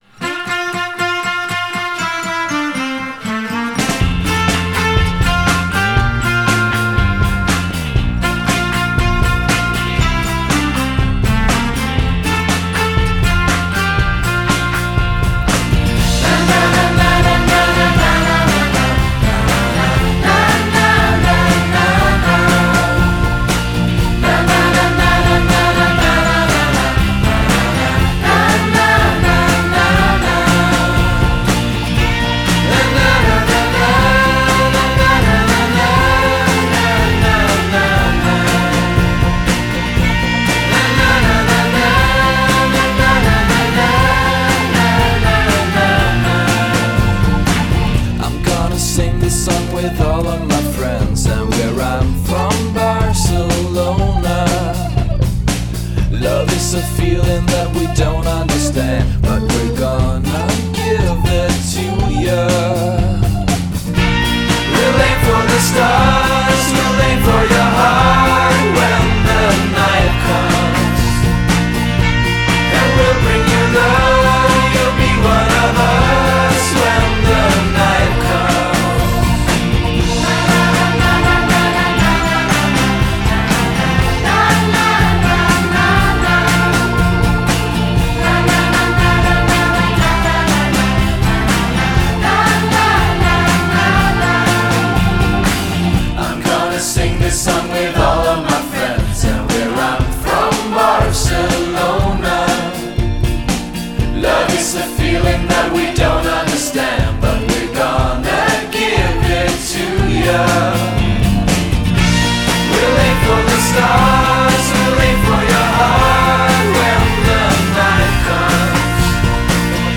chiassoso inno